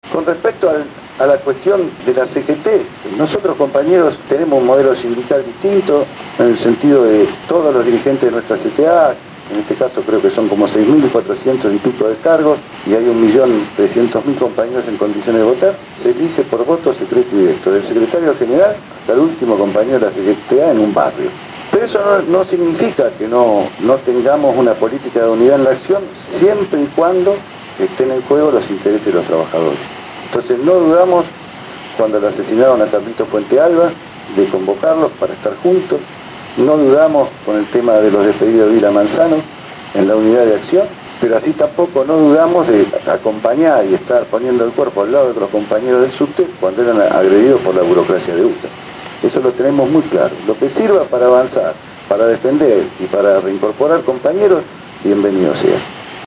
El último lunes se realizó en las instalaciones de Radio Gráfica la charla-debate: «Los Trabajadores y el Proyecto Nacional«, todo ésto de cara a las elecciones internas de la Central de Trabajadores de la Argentina el próximo 23 de Septiembre.